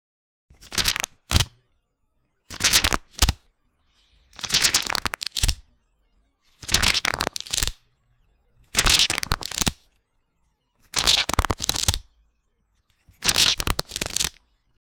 Laminated Photo Album - it just dropped off and now it's flipping it's pages on its own very very fast and it's not normal papar one it's laminated or can say plastic wrap 0:15 Created Apr 3, 2025 5:27 PM
laminated-photo-album---i-fwummpiv.wav